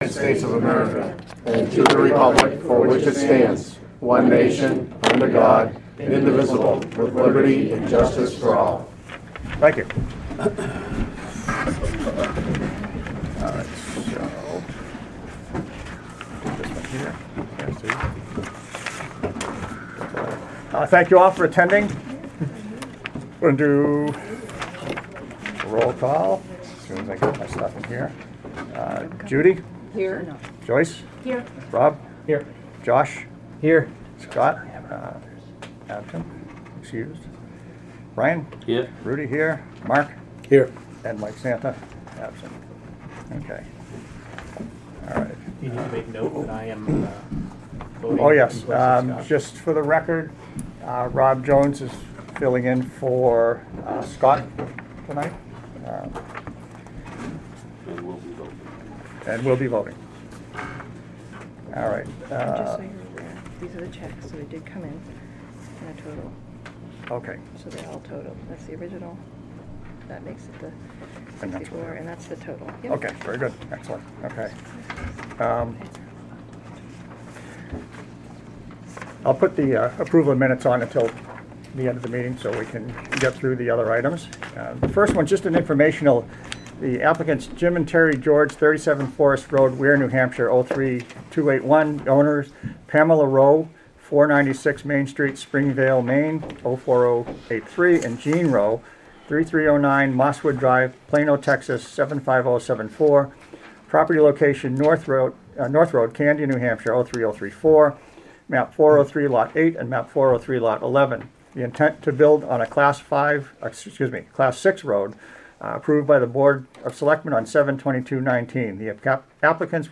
Audio recordings of committee and board meetings.
Planning Board Meeting